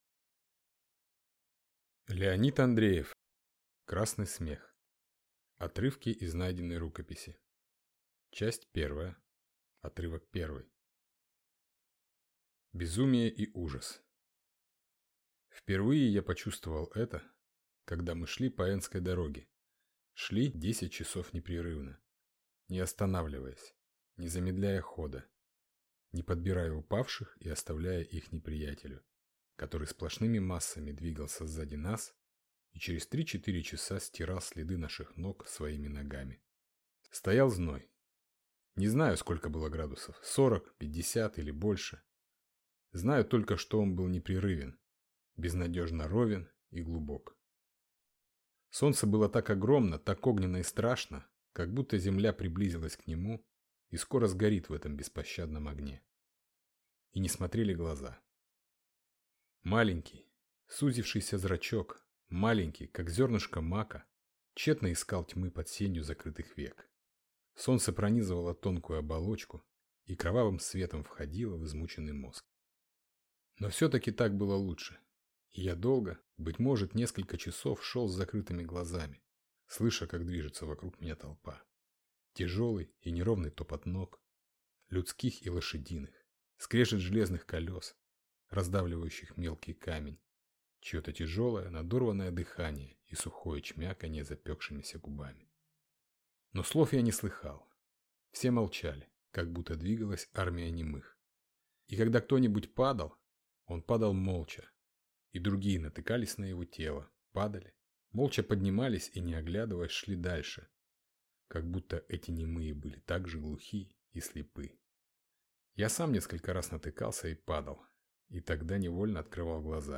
Аудиокнига Красный смех | Библиотека аудиокниг